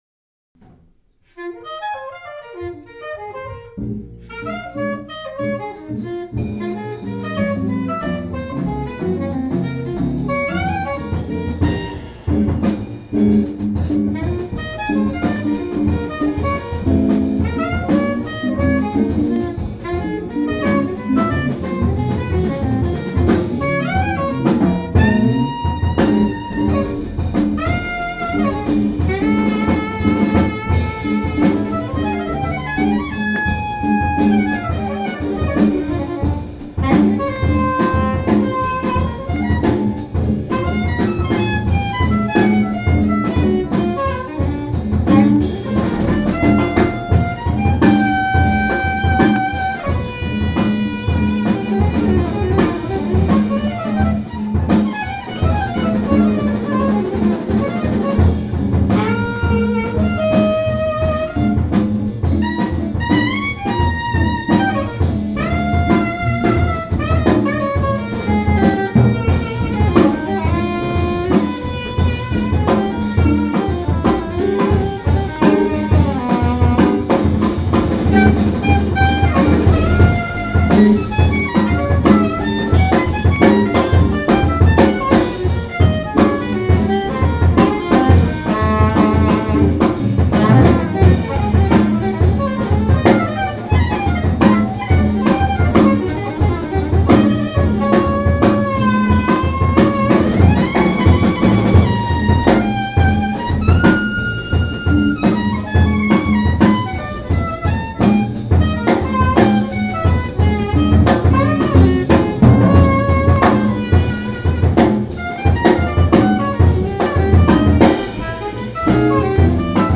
A variety of spoken improvisations or streams of vocalese mx with music of the moment and percussions of the past.